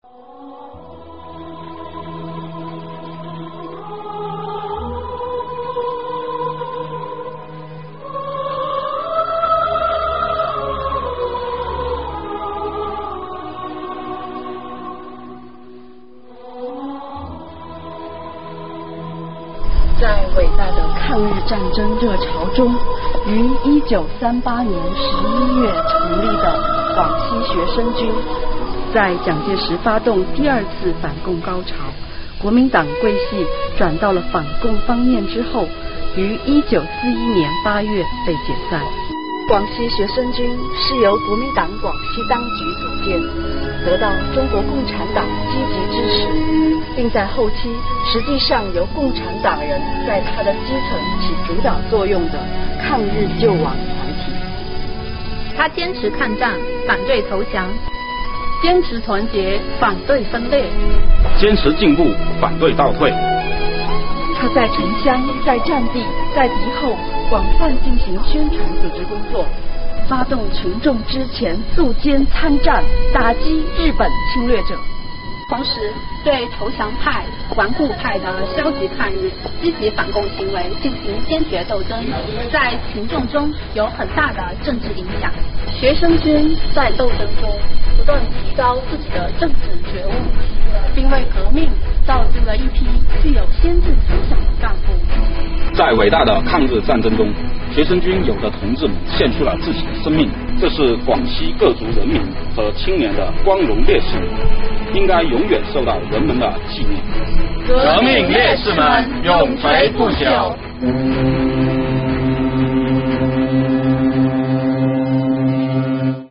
南宁青秀山风景区税务局青年党员干部到广西学生军抗日烈士纪念碑缅怀革命先烈，敬读碑文，从碑文中感受英雄们永远跳动的红心，从碑文中铭记先烈们为革命事业赴汤蹈火的壮举，从碑文中汲取“不忘初心 牢记使命”的信仰力量，坚定理想信念，立足岗位本职，走好新时代长征路。